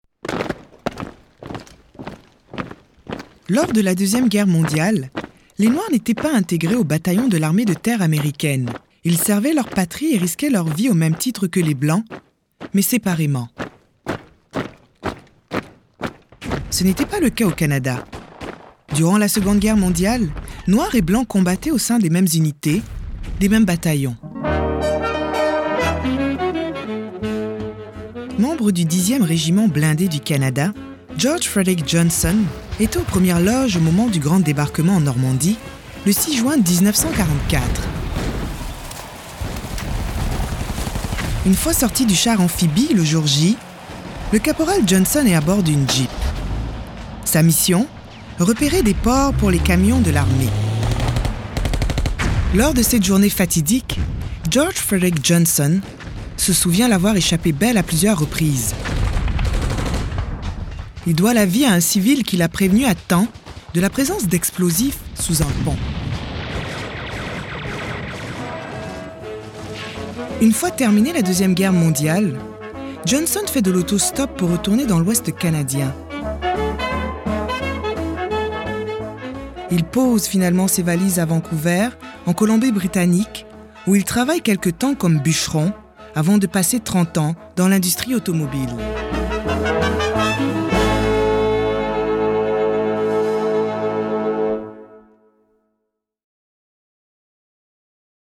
Narrateur